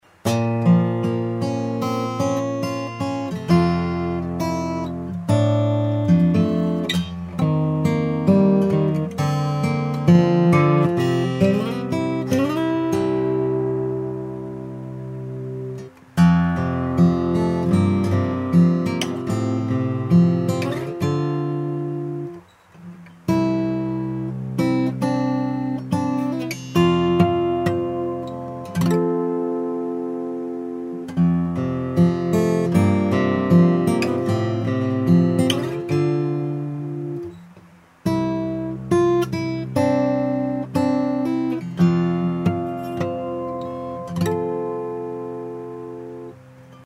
次はＧチューニングにしてみました。
♪Ｇチューニング
ハイポジションも音が合っています。